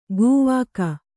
♪ gūvāka